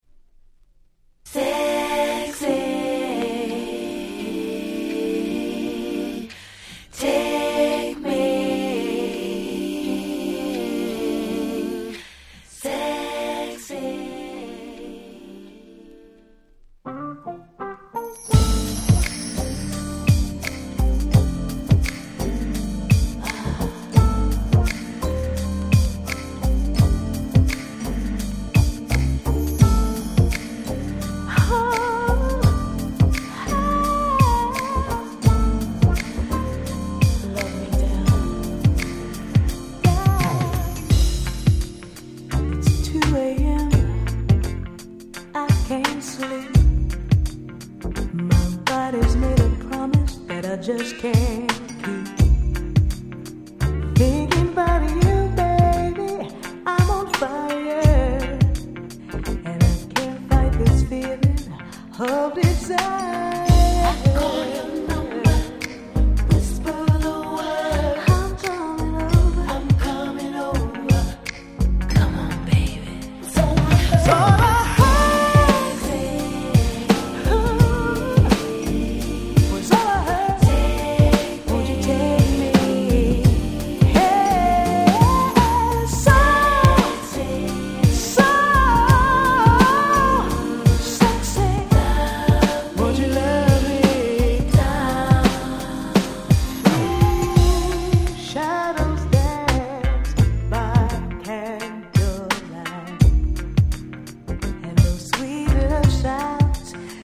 96' Nice UK R&B !!